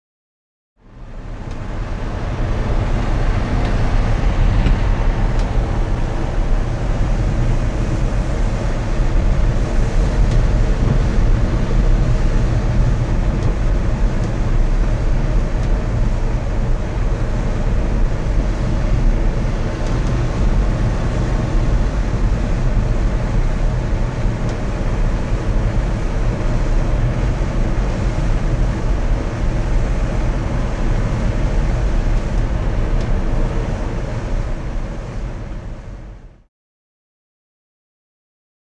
Glider; Interior; Interior Glider. Rushing Wind And Dull Low Throb.